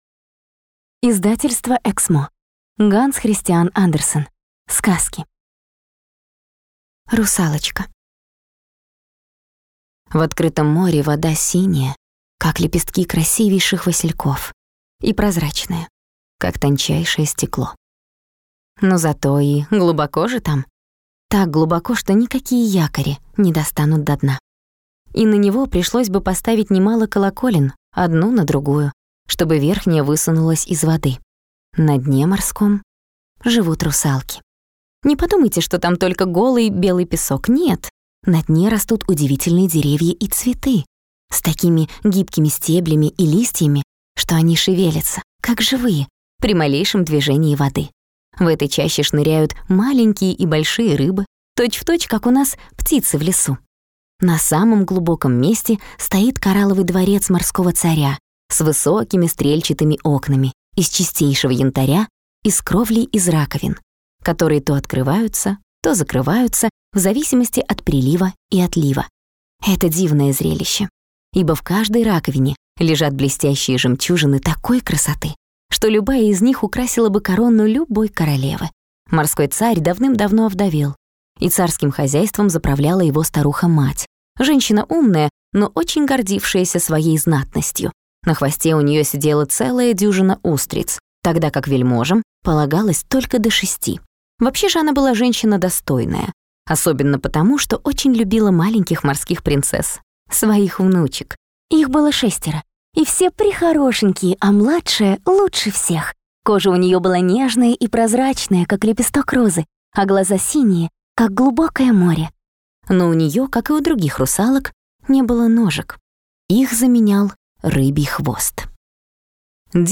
Аудиокнига Сказки | Библиотека аудиокниг